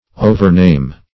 Search Result for " overname" : The Collaborative International Dictionary of English v.0.48: Overname \O`ver*name"\, v. t. To name over or in a series; to recount.